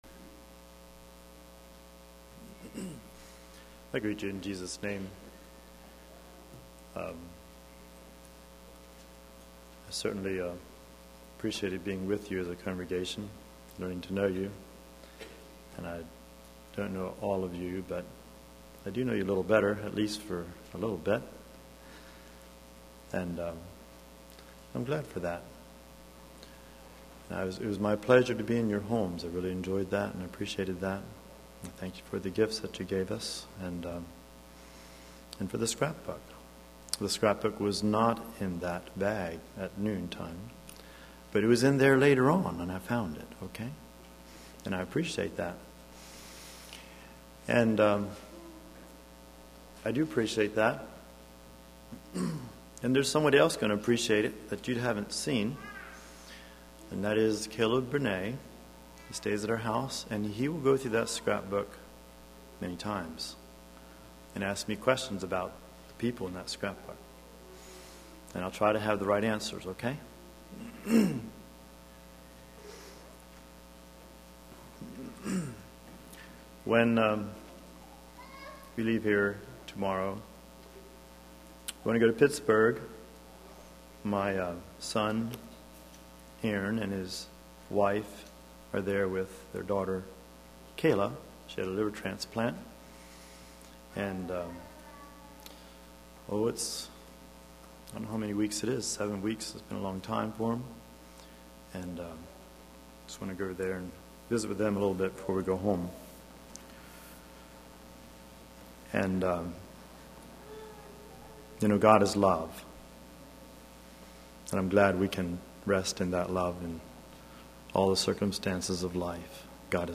2015 Sermon ID